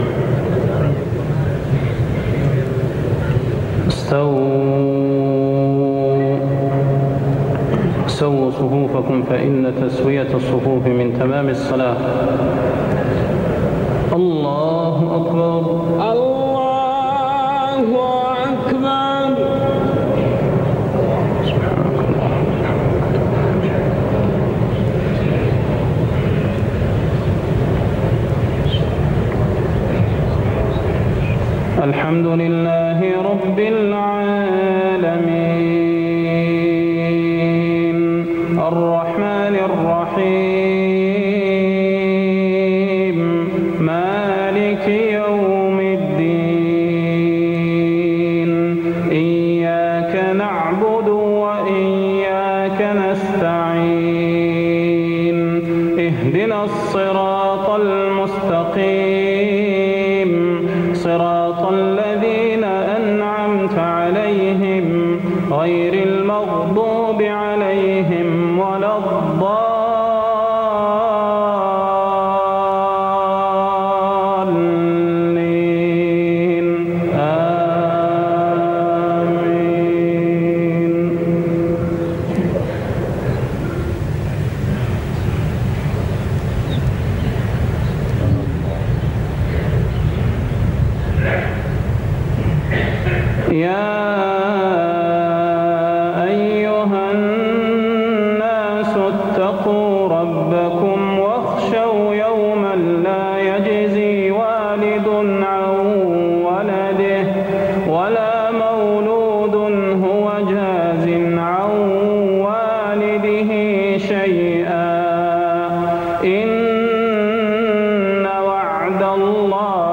صلاة المغرب 1425هـ خواتيم سورة لقمان 33-34 > 1425 🕌 > الفروض - تلاوات الحرمين